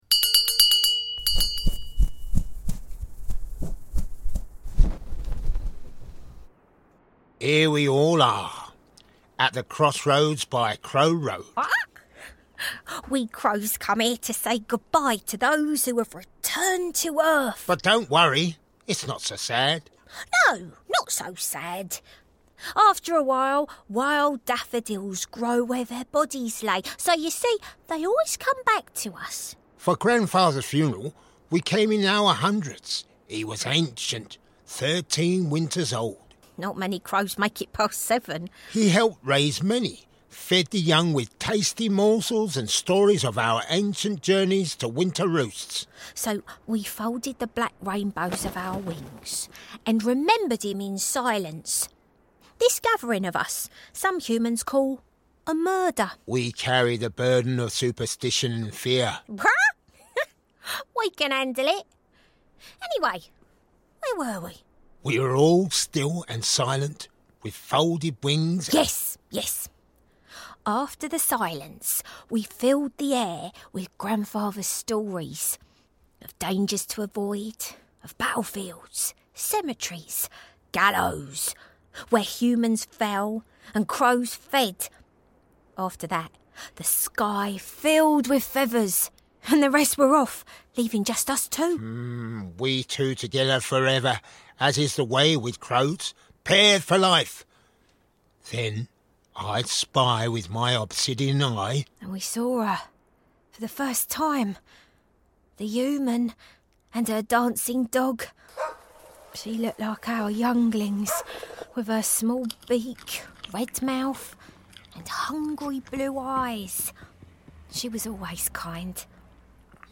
Lore of the Wild – an audio storytelling walk through the woods